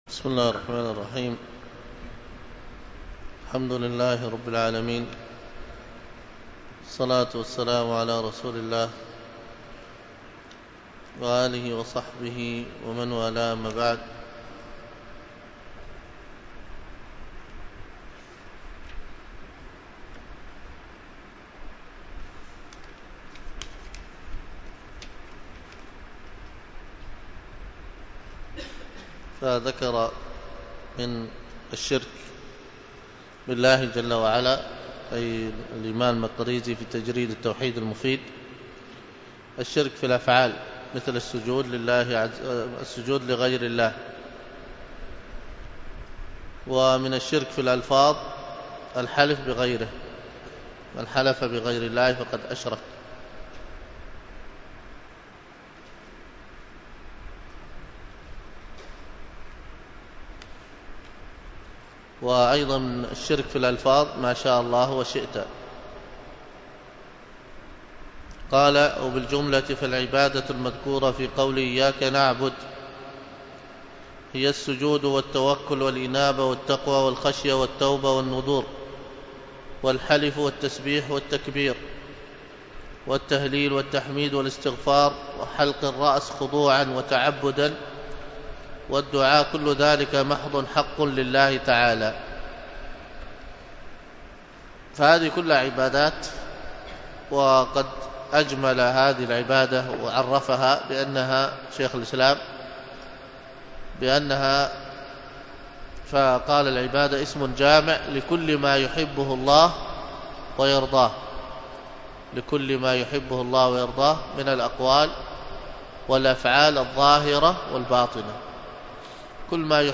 الدروس العقيدة ومباحثها